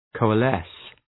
Προφορά
{,kəʋə’les}